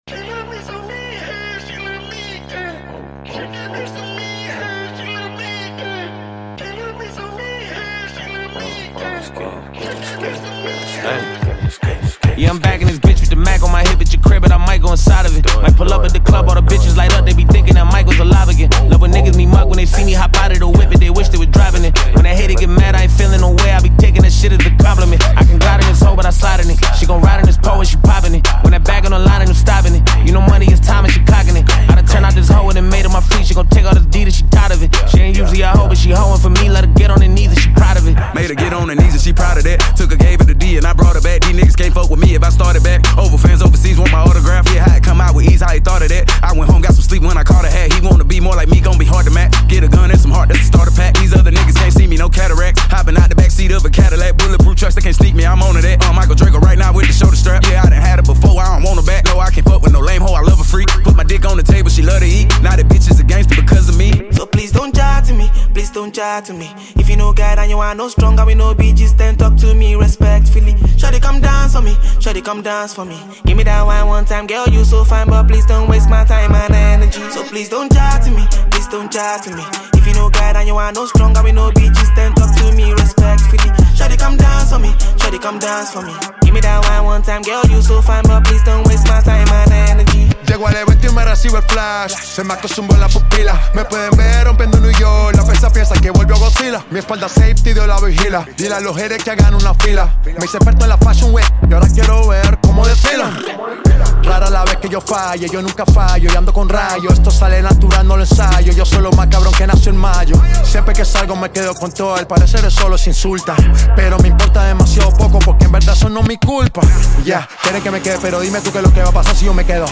delivers a smooth and engaging tune